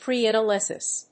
/ˌpriædʌˈlɛsɪs(米国英語), ˌpri:ædʌˈlesɪs(英国英語)/